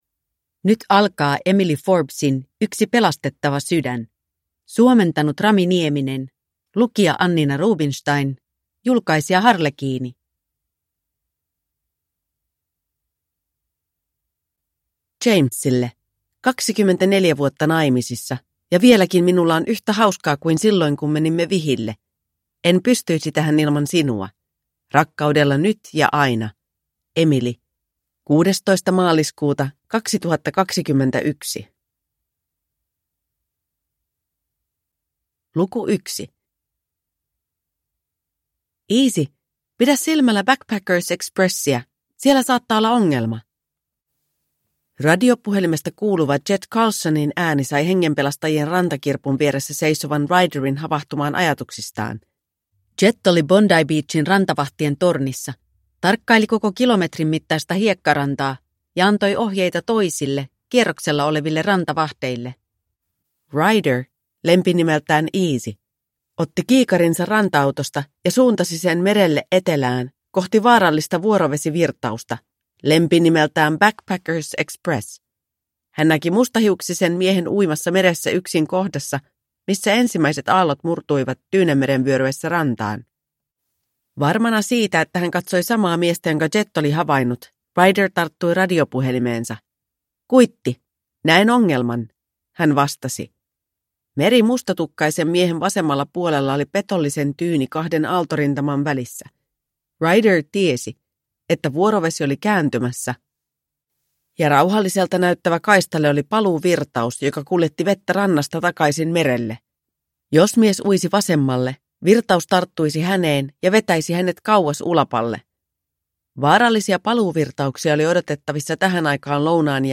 Yksi pelastettava sydän – Ljudbok